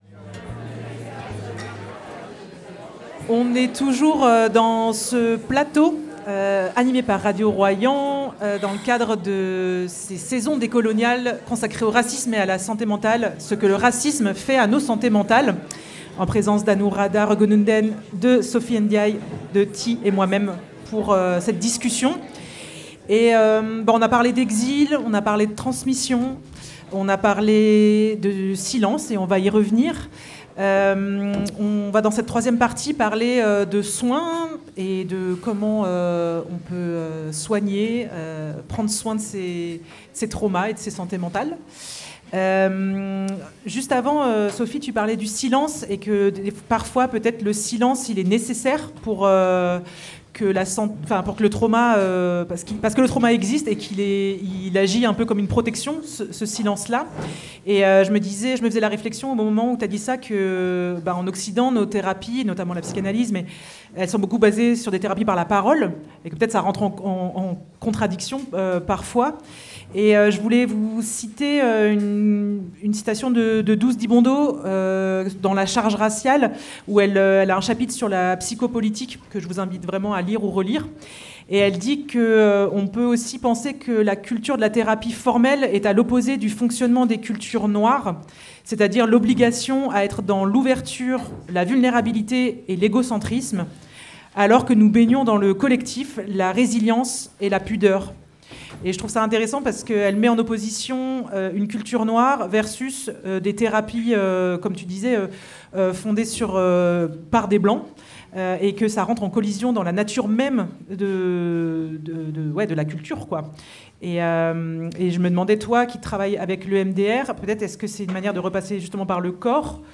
Posté par Radio Royans-Vercors | 3 Mar 2026 | ACTUALITÉS, Hors les murs | 0 |